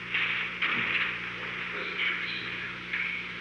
Recording Device: Oval Office
The Oval Office taping system captured this recording, which is known as Conversation 690-012 of the White House Tapes.